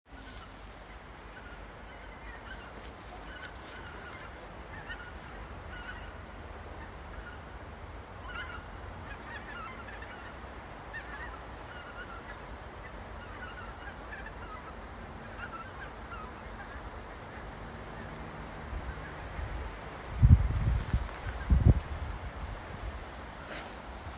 Goose Anser sp., Anser sp.
Administratīvā teritorijaĀdažu novads
NotesAr zossu balss kliedzieniem.